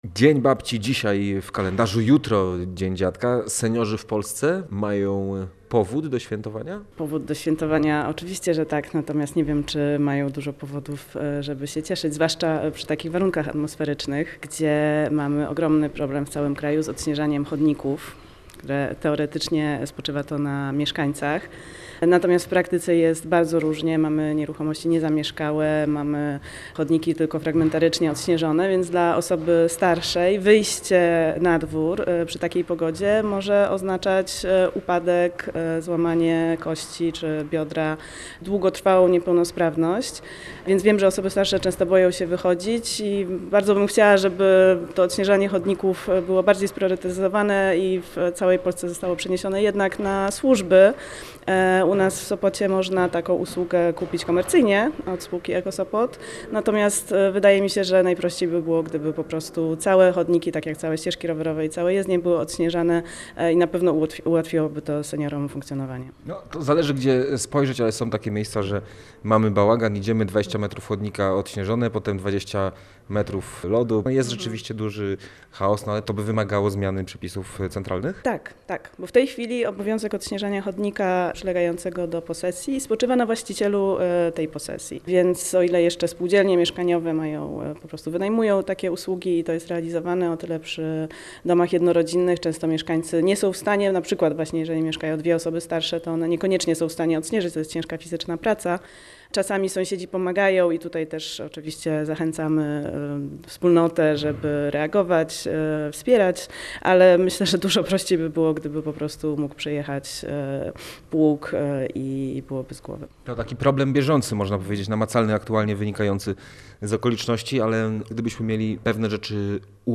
Jak tłumaczyła w rozmowie z Radiem Gdańsk, aktualne przepisy potęgują chaos w utrzymaniu traktów pieszych, a najbardziej cierpią na tym seniorzy.